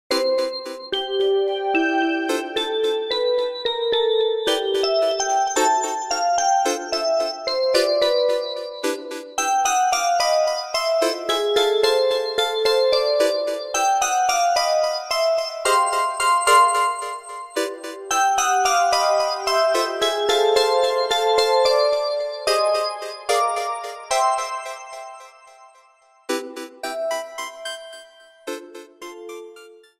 Trimmed & faded out
Fair use music sample